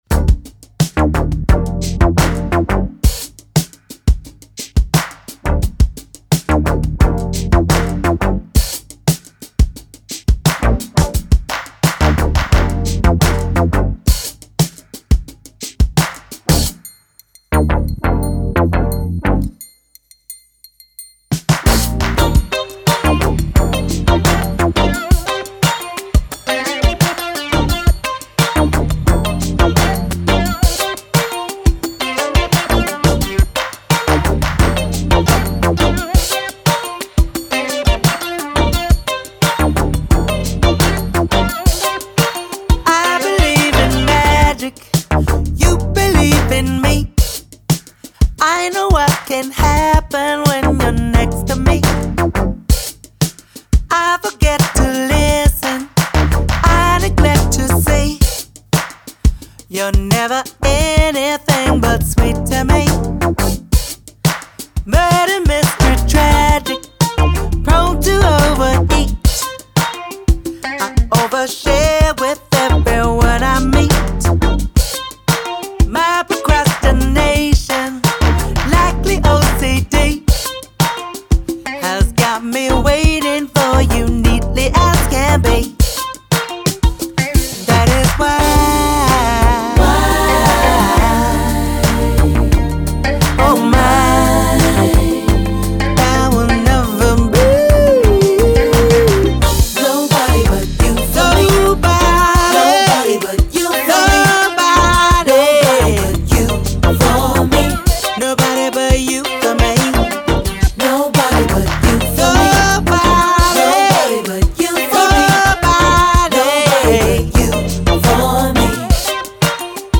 Genre : Electro Funk, Soul, RnB.